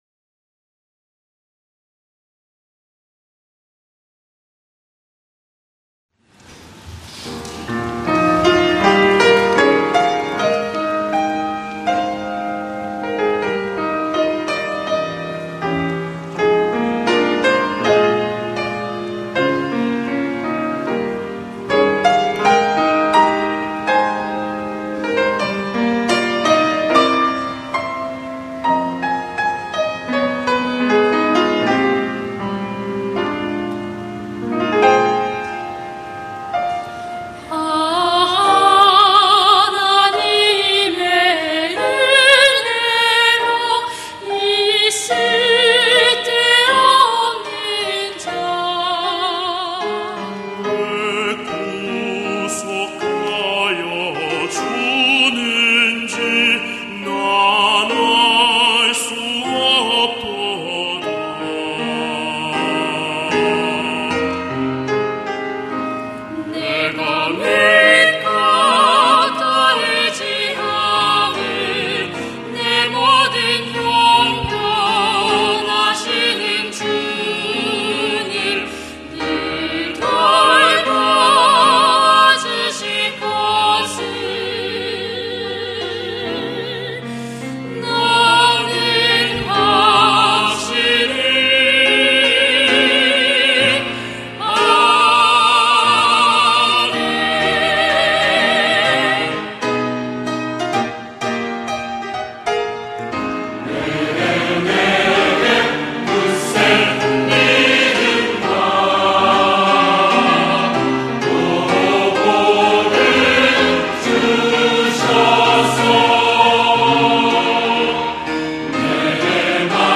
아 하나님의 은혜로 > 찬양영상